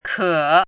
chinese-voice - 汉字语音库
ke3.mp3